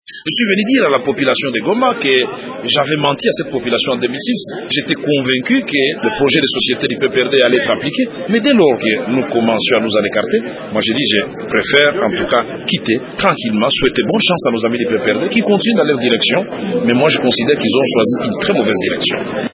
Vital Kamerhe a déclaré, mercredi à Goma: